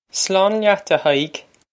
Pronunciation for how to say
Slawn lyat, a Hi-ig!
This is an approximate phonetic pronunciation of the phrase.